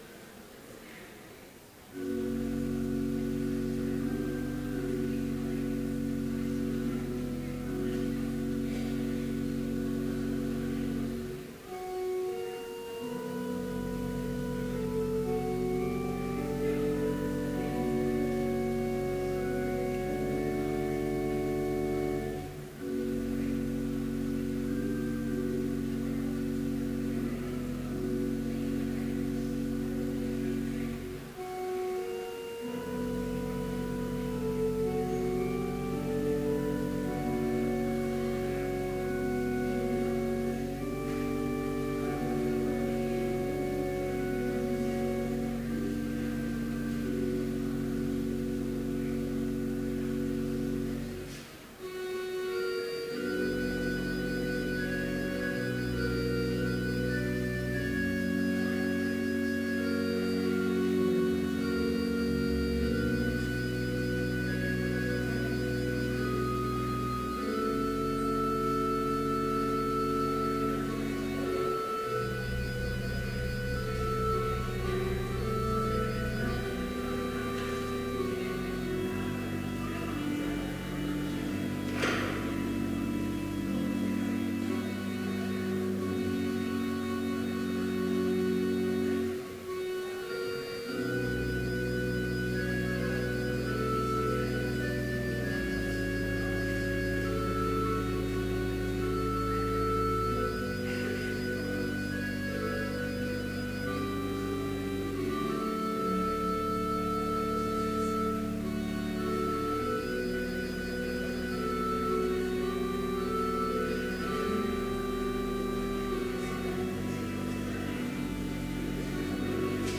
Complete service audio for Chapel - January 29, 2015